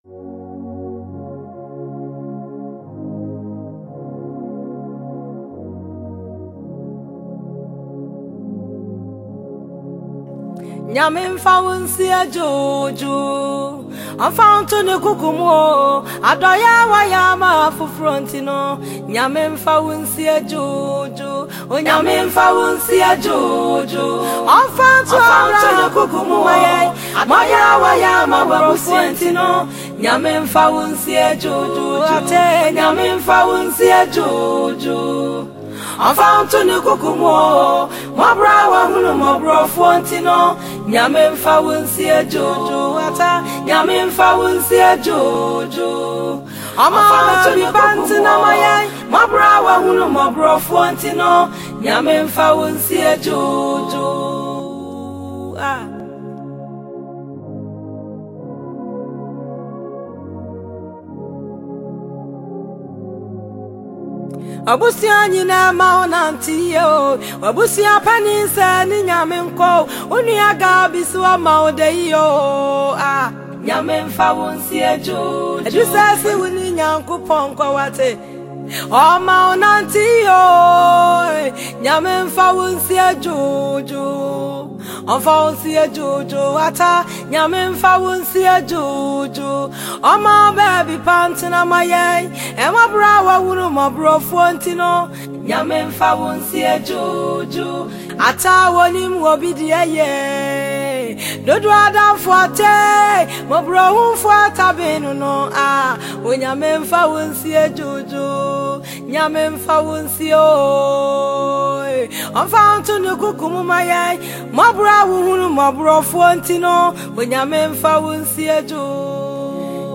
Ghanaian female Gospel singer